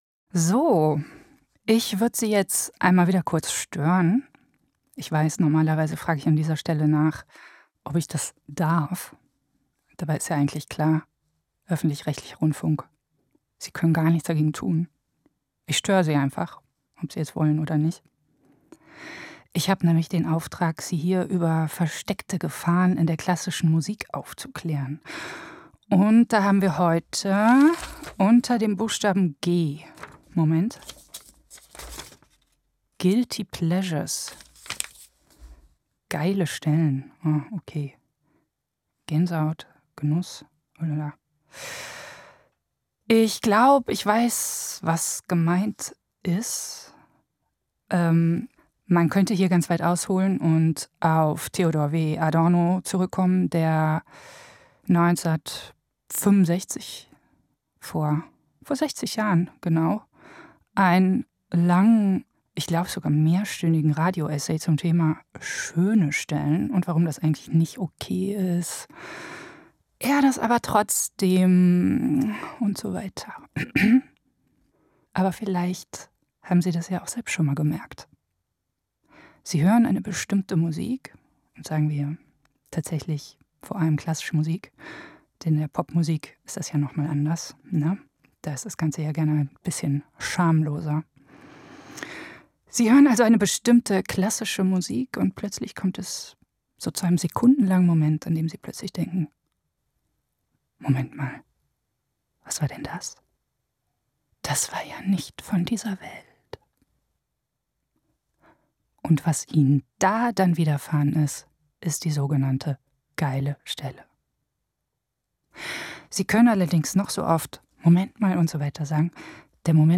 Glosse